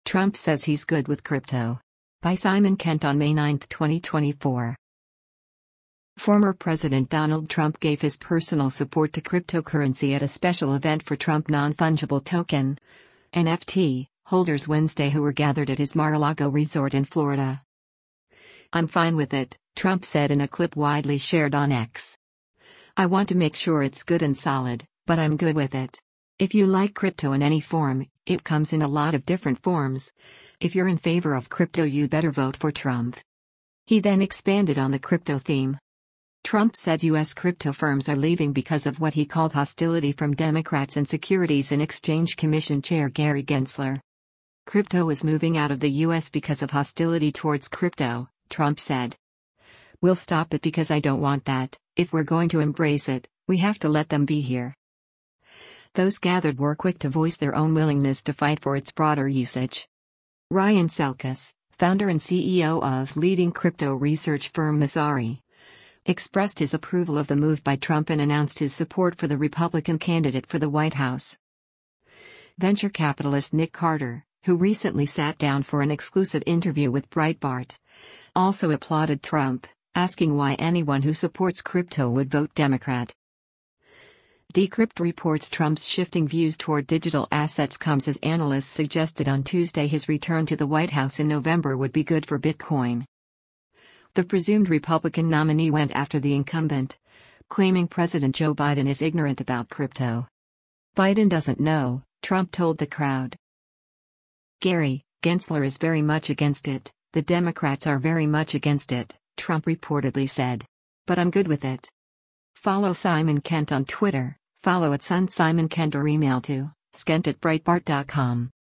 Former President Donald Trump gave his personal support to cryptocurrency at a special event for Trump non-fungible token (NFT) holders Wednesday who were gathered at his Mar-a-Lago resort in Florida.